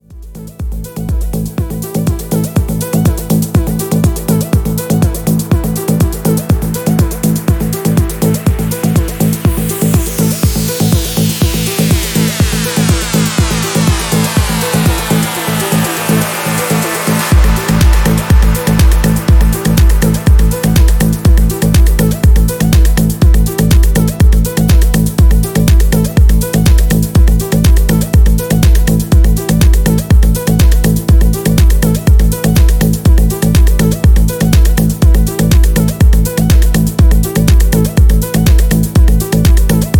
• Качество: 320, Stereo
атмосферные
EDM
электронная музыка
без слов
басы
Melodic house